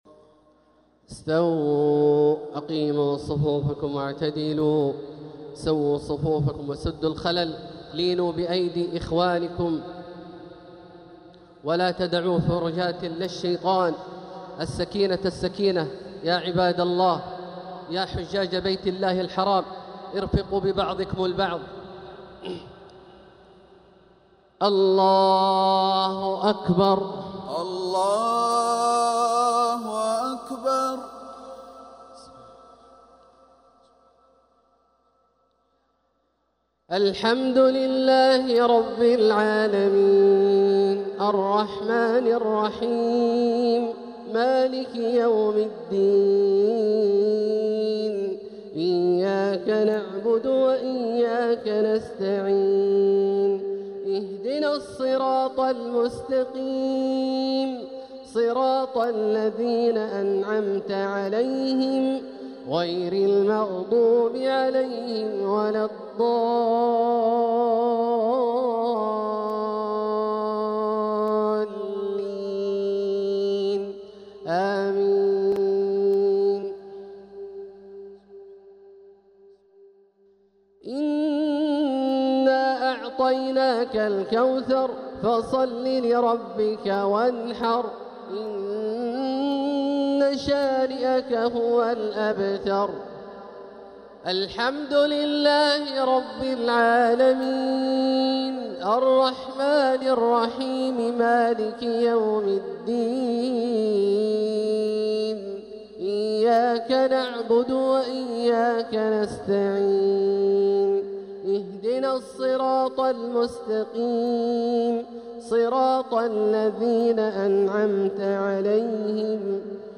Jumaah prayer Surat Al-Kawthar and An-Nasr 6-6-2025 > 1446 > Prayers - Abdullah Al-Juhani Recitations